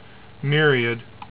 "NEER ee ed" ) is the outermost of Neptune's known satellites and the third largest: